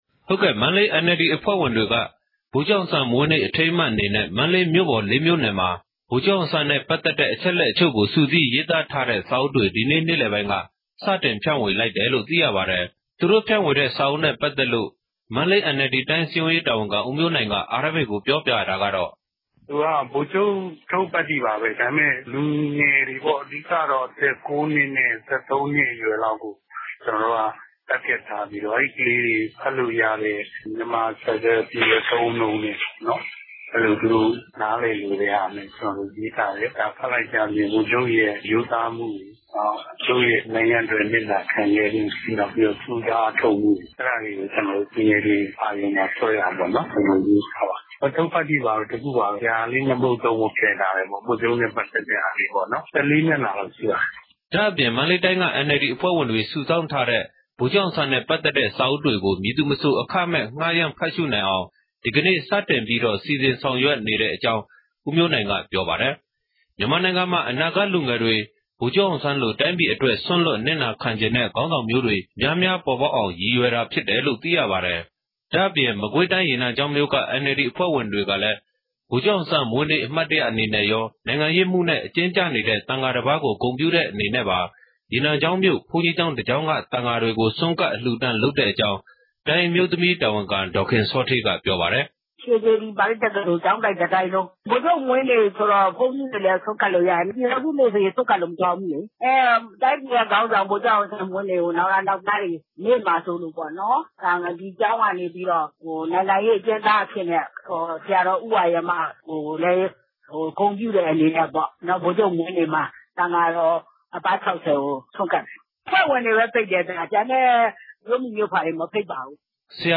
သတင်းပေးပို့ချက်။